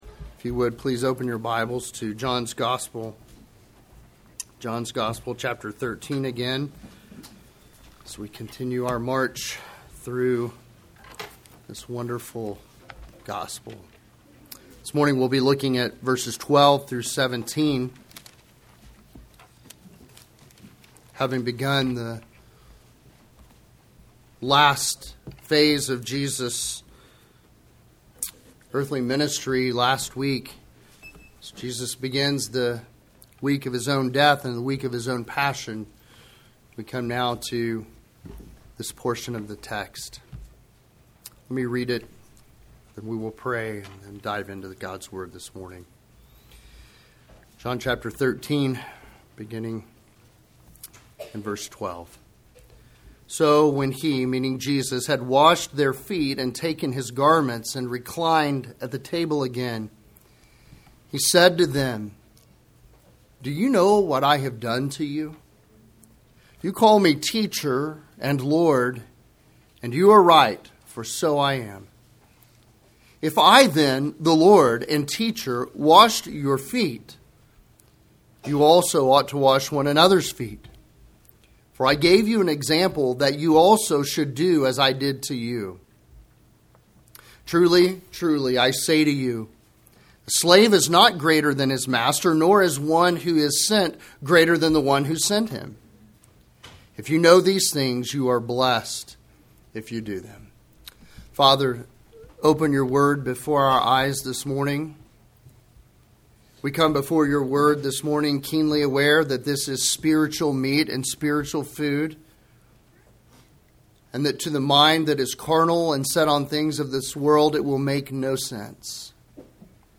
Sermons Podcaster